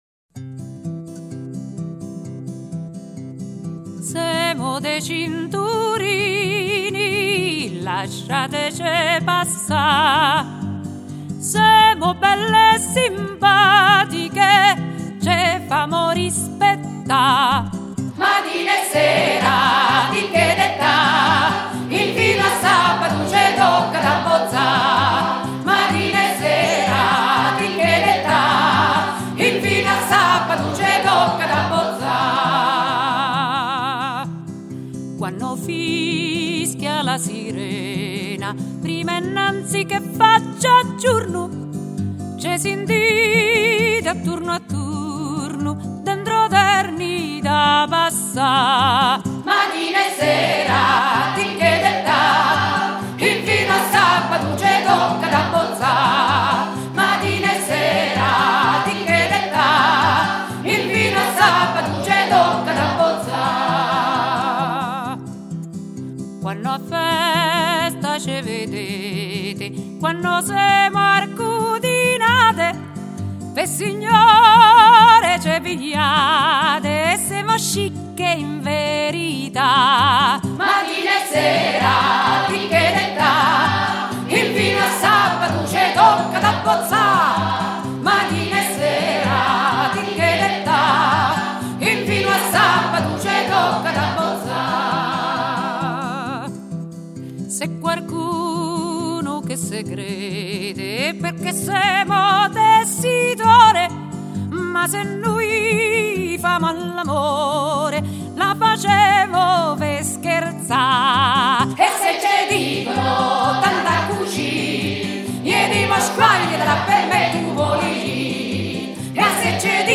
L'albero del canto - coro di canti della tradizione italiana
L'Albero del Canto